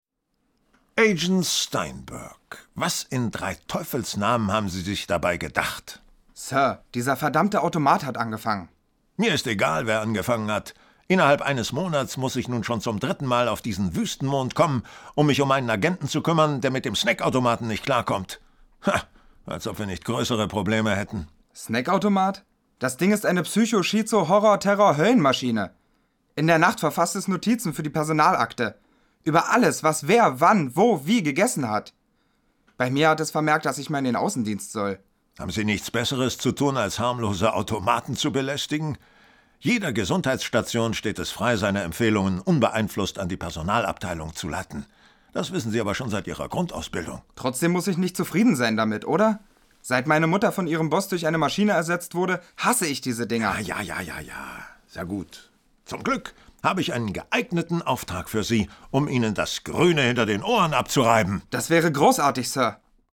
Sprechprobe: Werbung (Muttersprache):
german voice over artist